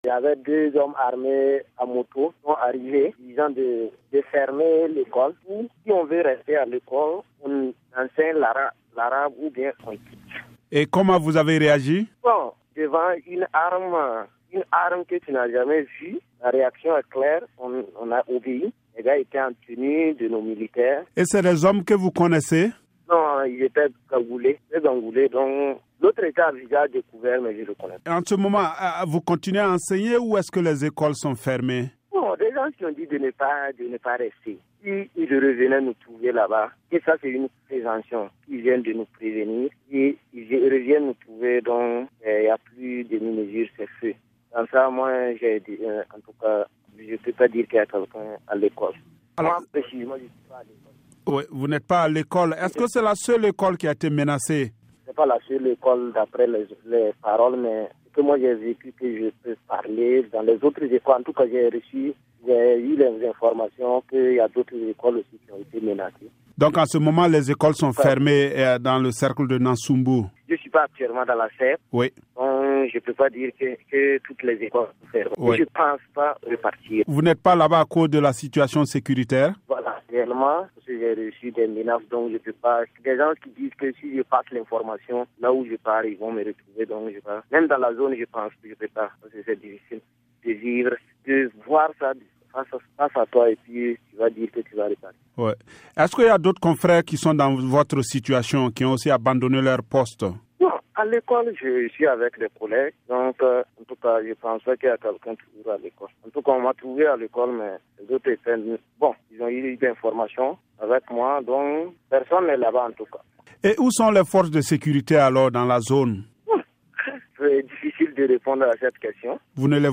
enseignant ayant fui le nord du Burkina